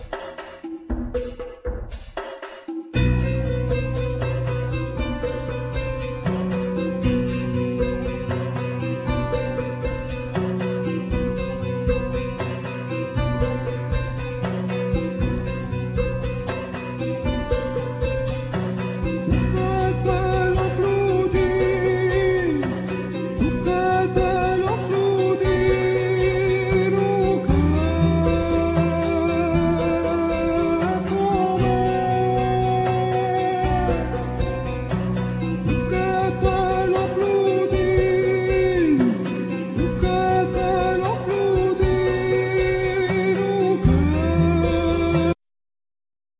Acoustic & Electric guitar,Bass
Piano,Synthsizer
Voice,Tenor Saxphone
Timpani
Small Clarinet
Tambur
Oboe